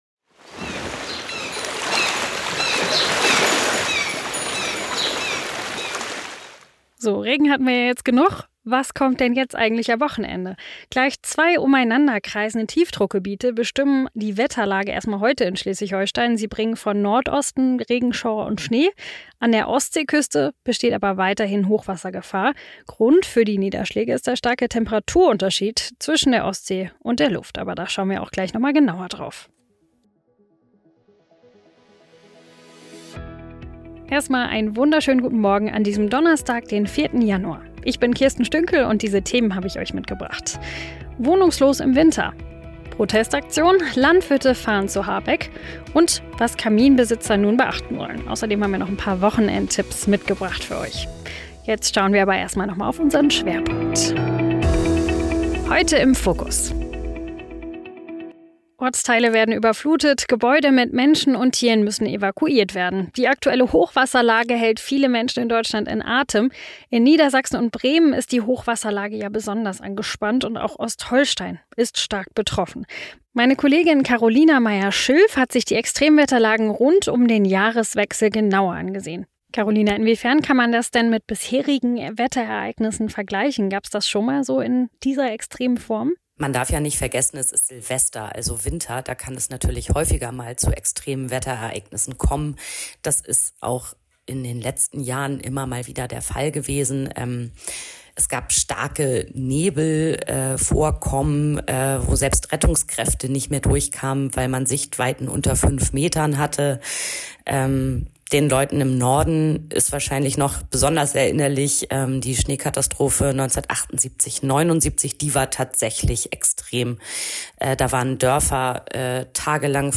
Dein News-Podcast für Schleswig-Holstein
Nachrichten